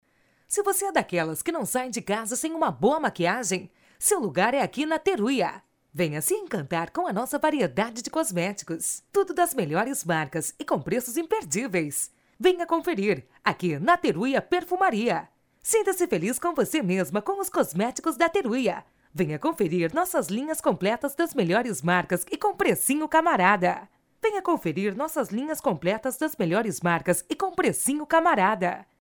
animada: